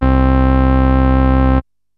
VINTAGE ORG 1.wav